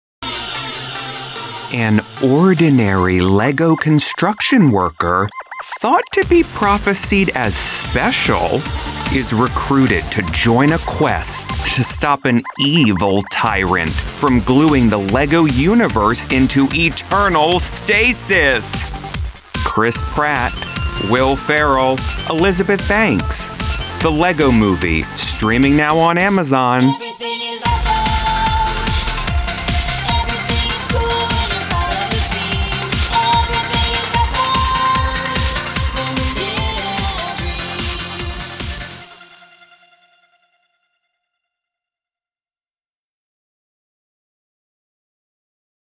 Middle Aged
My reads land with confidence and playfulness.